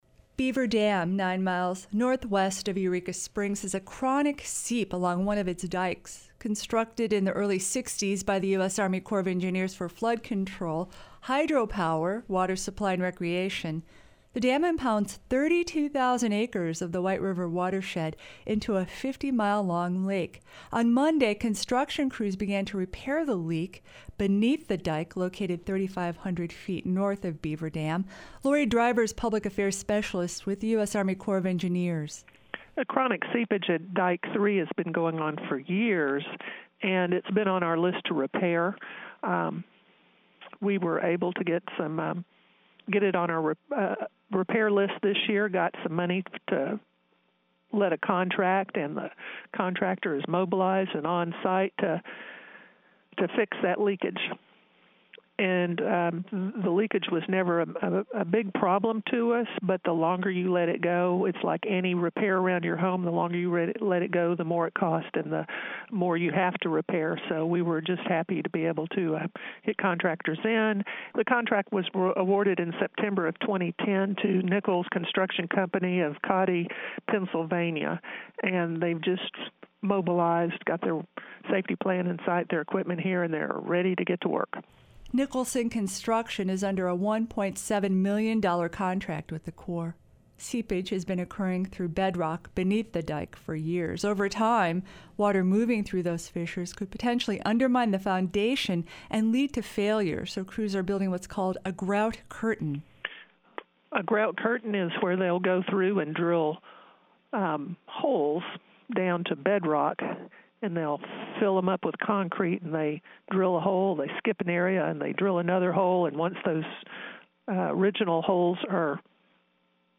Ozarks At Large | KUAF 91.3FM